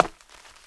HARDWOOD A.WAV